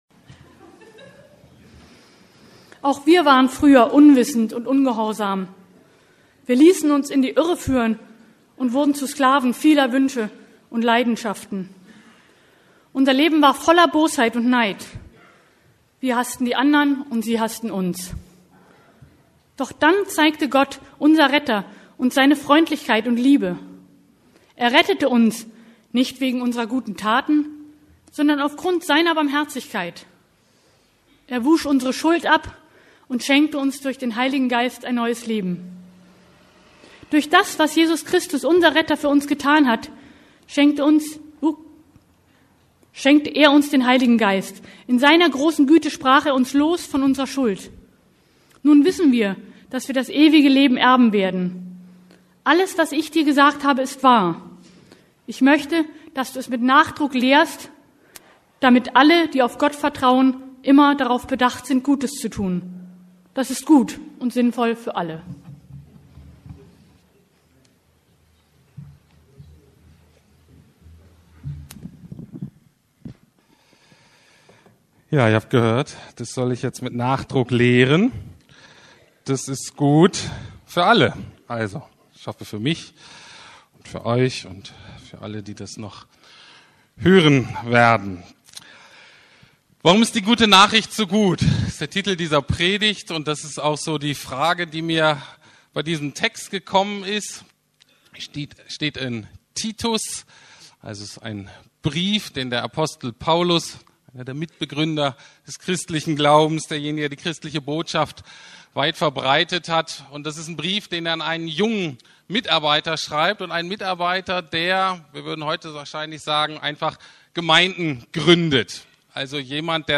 ~ Predigten der LUKAS GEMEINDE Podcast